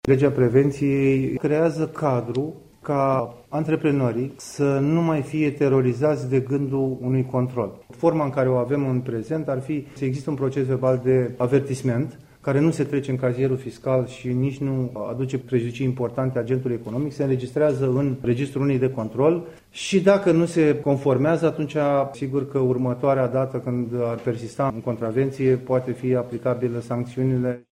Secretarul de stat în Ministerul pentru Mediul de Afaceri, Cristian Dima, a explicat, astăzi, la Galaţi, că proiectul vizează prevenirea infracţiunilor economice în detrimentul pedepsirii antreprenorilor: